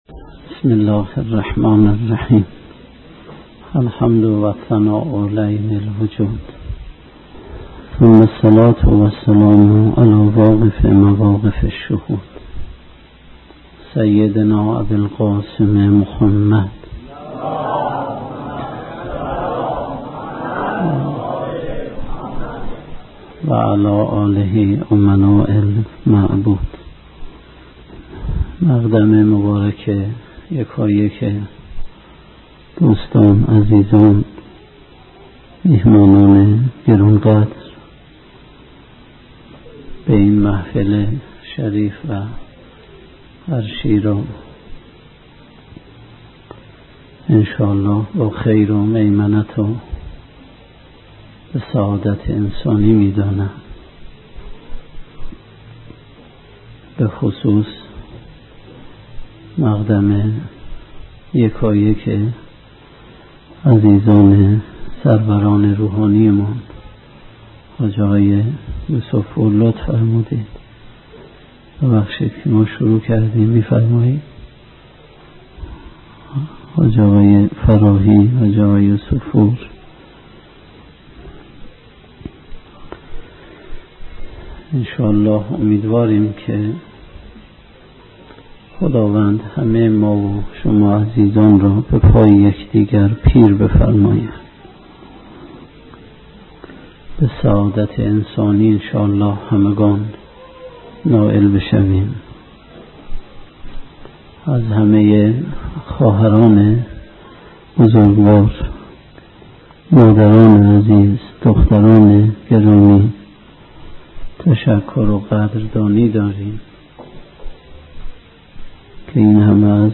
سخنرانی
در لحظه ی تحویل سال